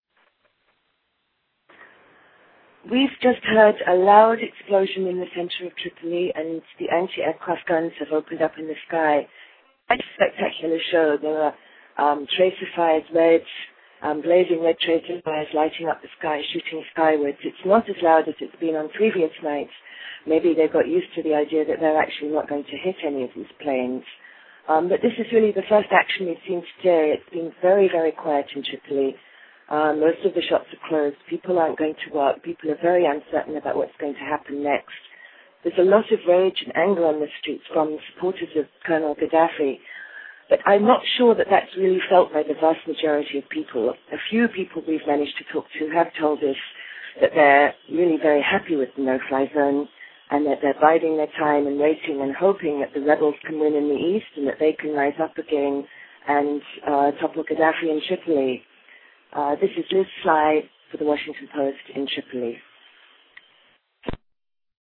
reports from Tripoli, Libya